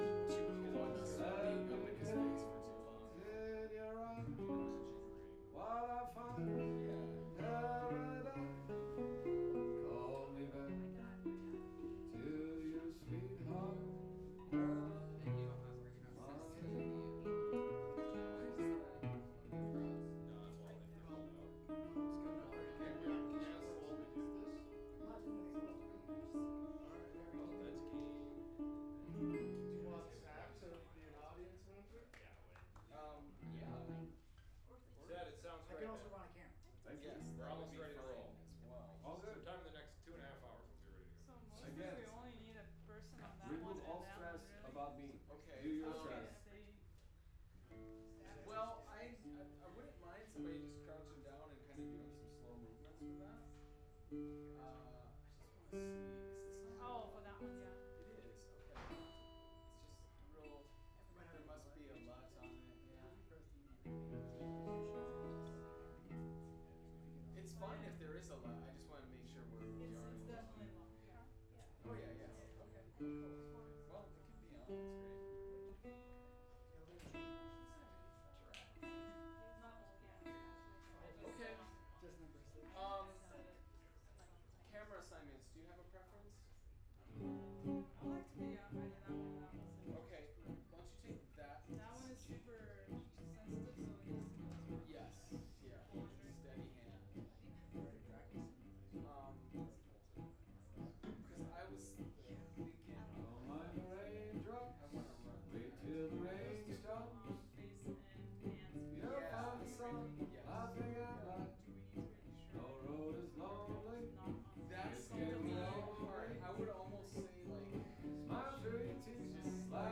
VP88_01 R.wav